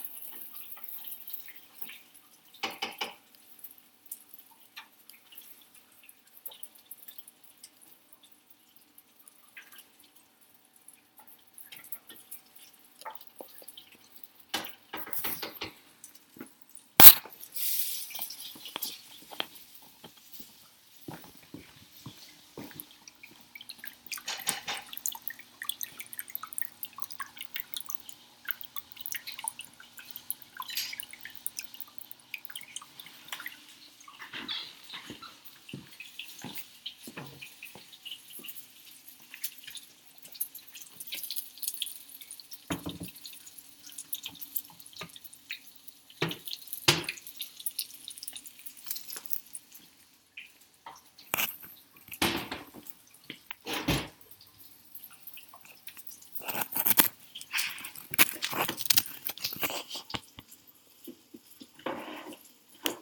LOCATION: The kitchen right after our show for BBQ!
SOUNDS: the sizzling of shrimp being fried up tempura style, the bubbling of the fish tank in the adjacent room, footsteps shuffling around, cabinets and drawers being opened and closed.
Field-Recording-2-4.mp3